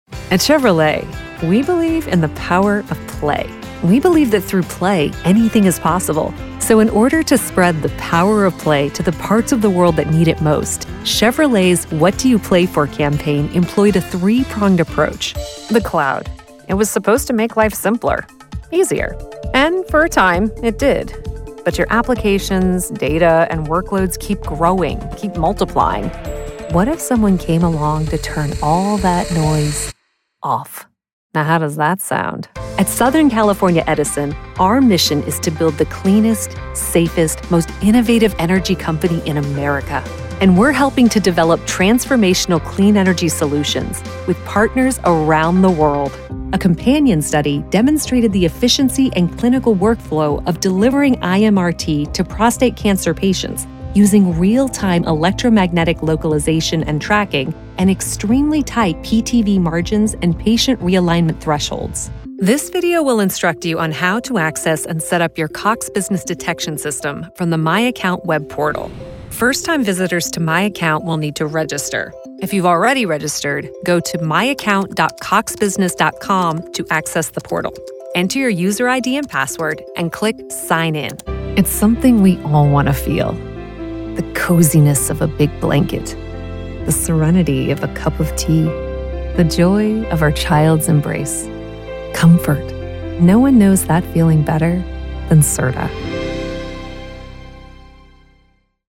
Corporate Narration Demo